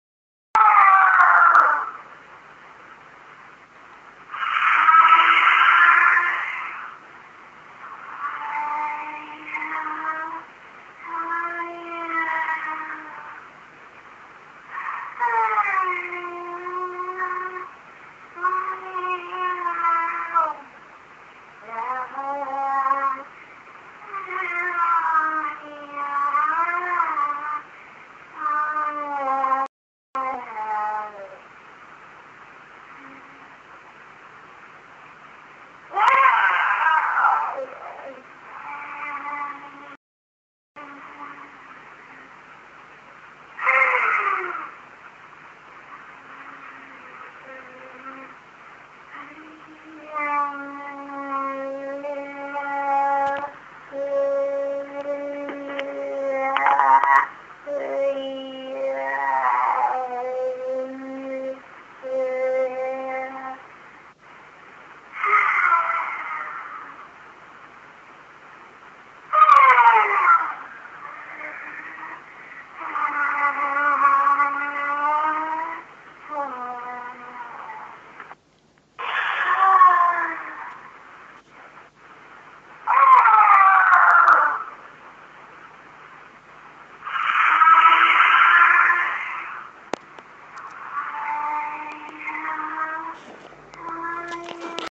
Здесь вы можете слушать и скачивать аудиофайлы, создающие атмосферу таинственности и страха. Подборка включает различные вариации звуков – от шепота до жуткого смеха.
Момо разговаривает по телефону